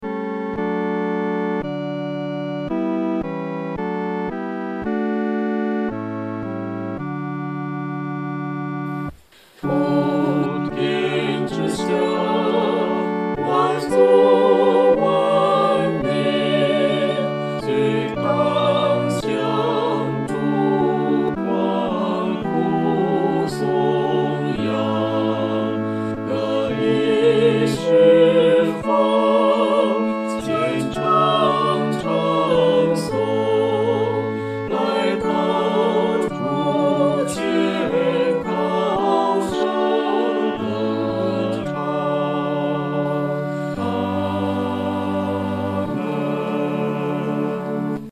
合唱
四声
本首圣诗由石家庄圣诗班录制